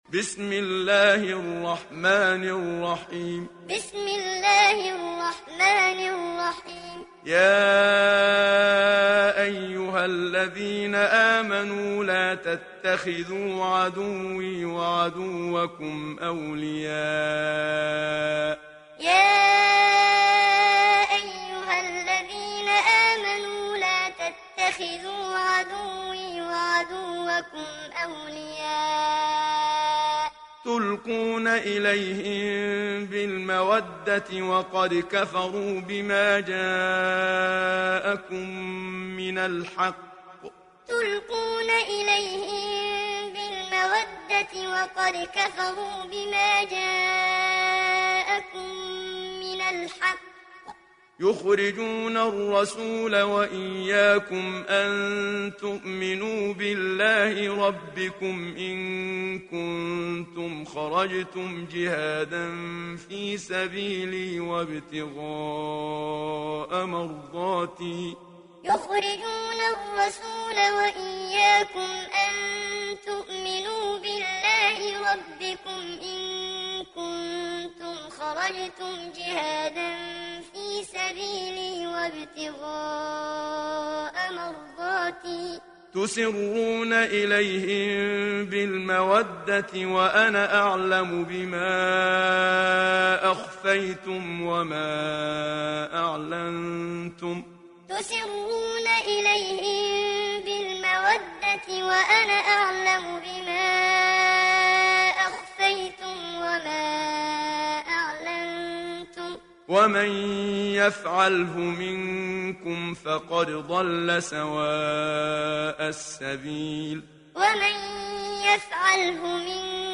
دانلود سوره الممتحنه محمد صديق المنشاوي معلم